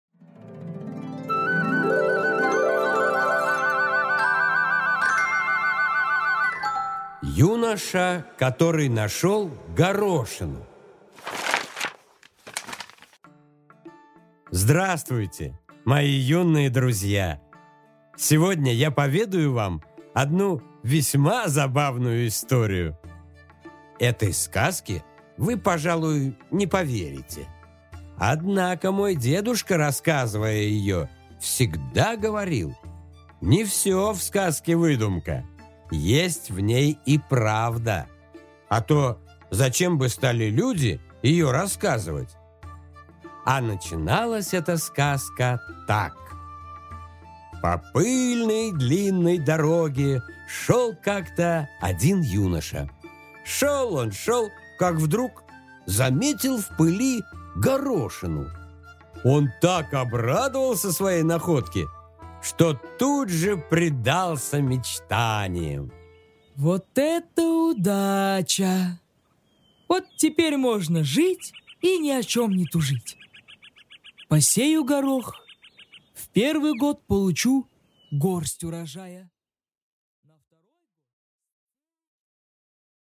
Аудиокнига Юноша, который нашёл горошину